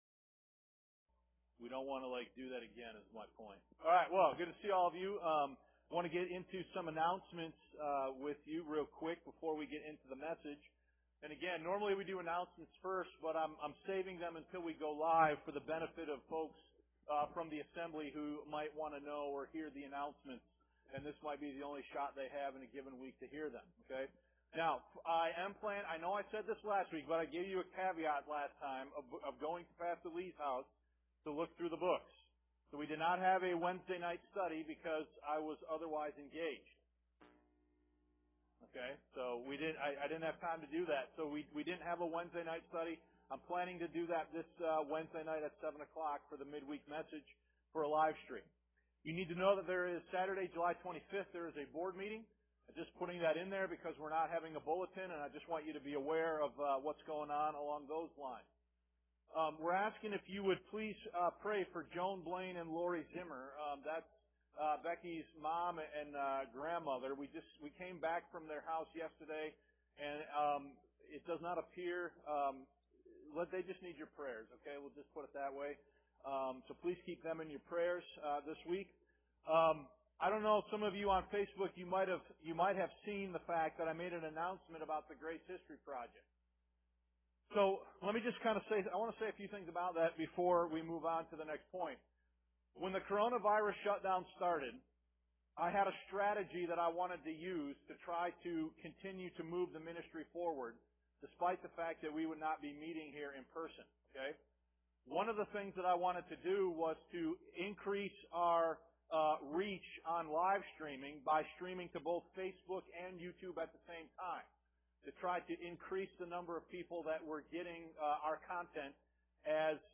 Note: The Preaching Starts @ 16 minute mark.